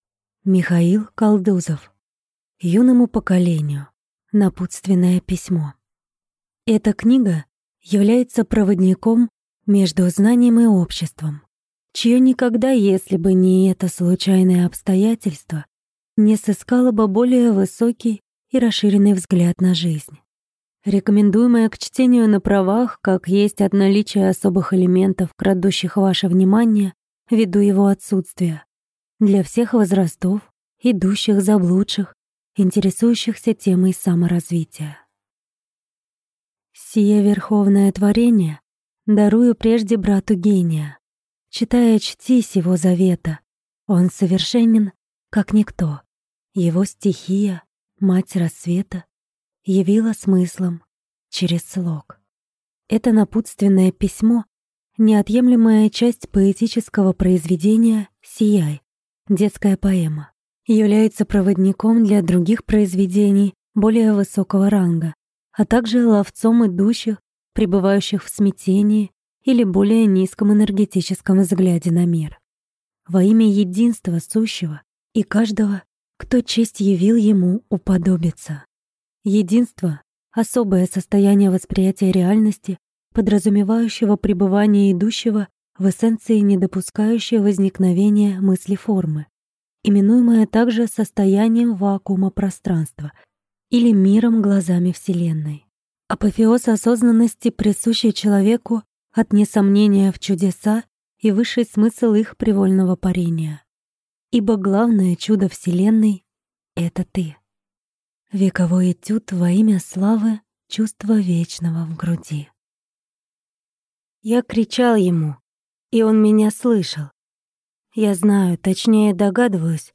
Аудиокнига Юному поколению. Напутственное письмо | Библиотека аудиокниг